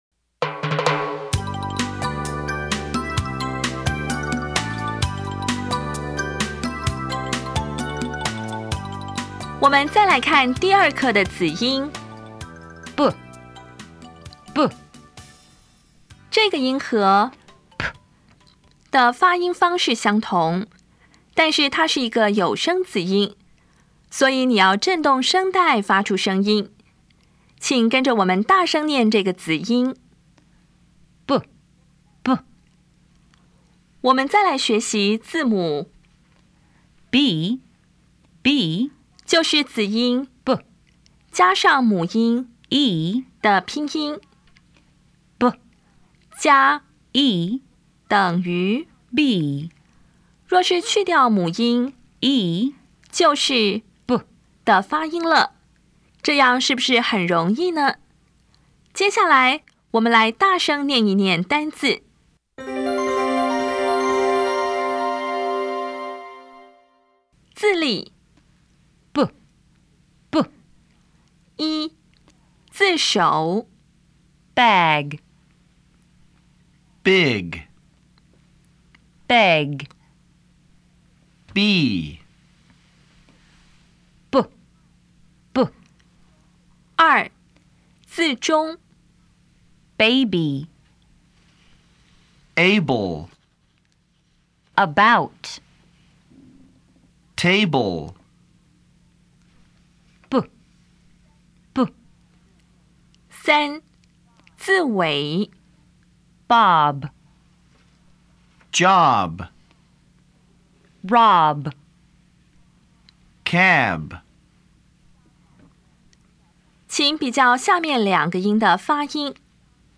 音标讲解第二课字例
比较[p][b]       [p](无声) [b](有声)
Listening Test 1